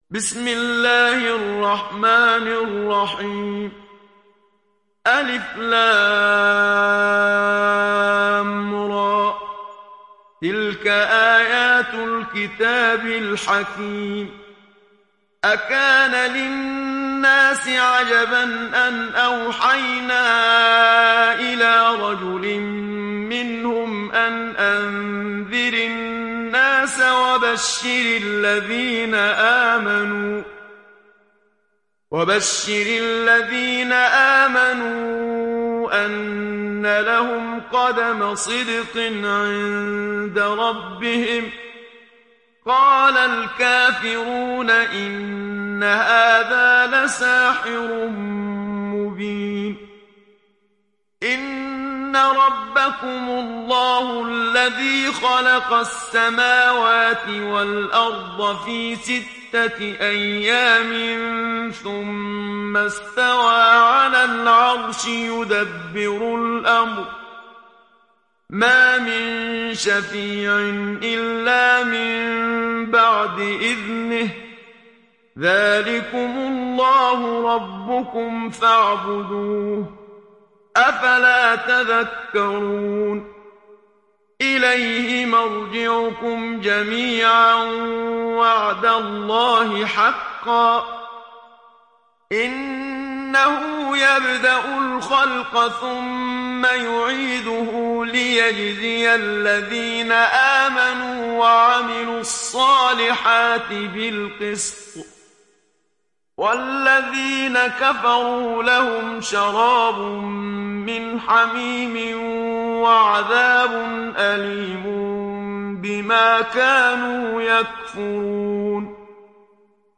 Sourate Yunus Télécharger mp3 Muhammad Siddiq Minshawi Riwayat Hafs an Assim, Téléchargez le Coran et écoutez les liens directs complets mp3